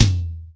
TOM     3A.wav